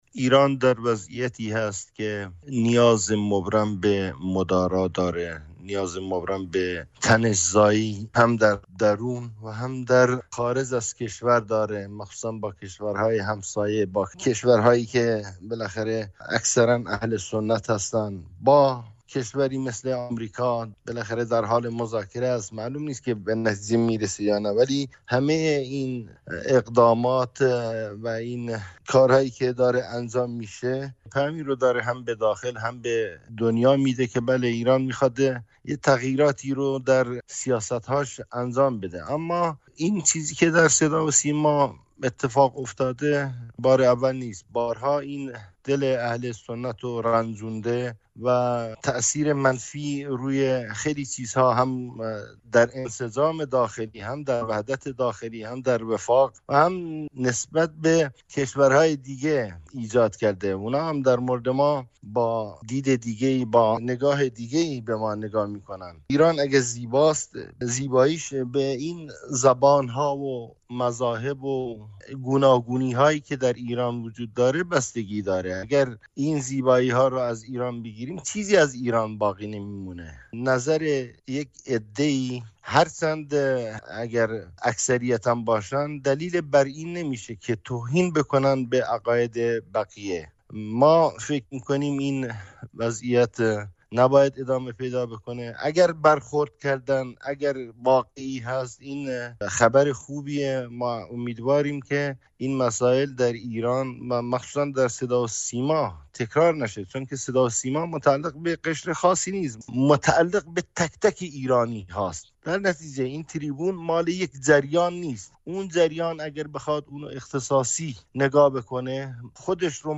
حاصل داسه، فعال سیاسی کرد اهل نست و نمایندۀ سابق مجلس شورای اسلامی در گفت‌وگو با رادیوفردا می‌گوید تکرار توهین به باورهای اهل سنت در صداوسیما به گزینش‌های انحصاری این سازمان بازمی‌گردد و با برخوردهای موردی این مسئله حل نمی‌شود.